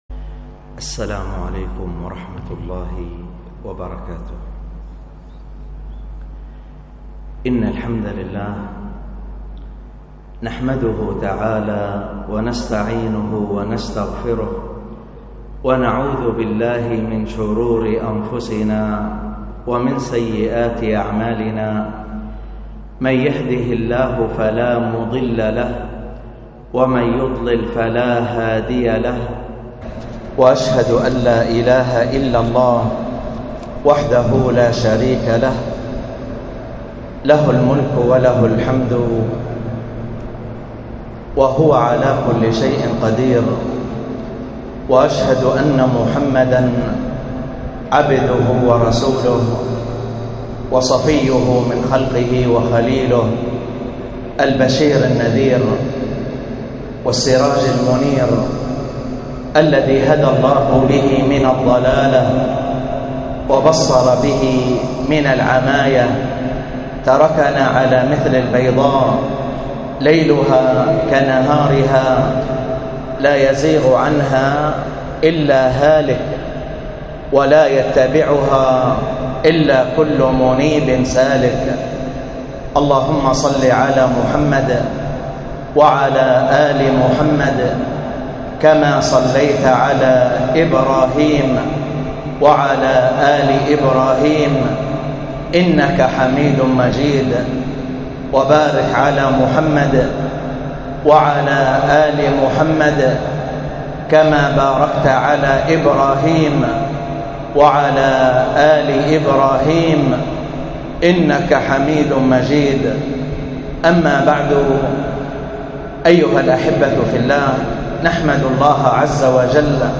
الدرس في كتاب البيع 13، ألقاها